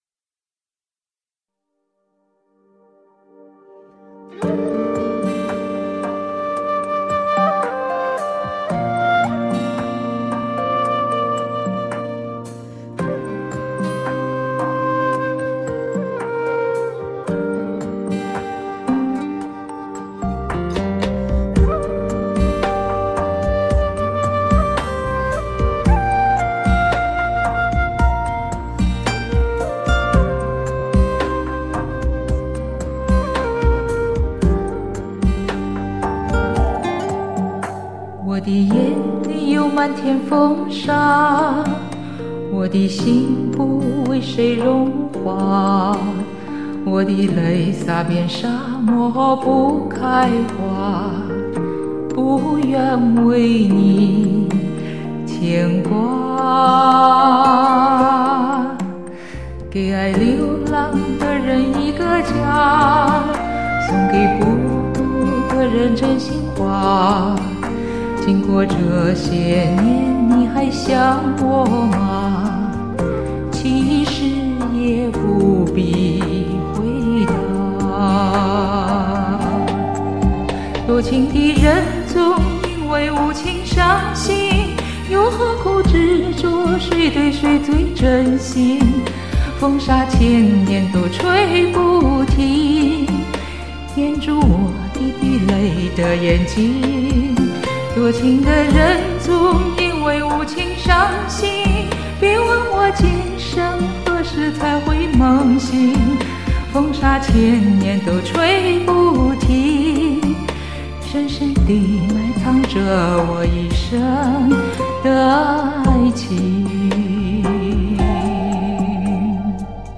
升了一个音，实在唱不了她那么低。
但感到一种台湾校园歌曲的风格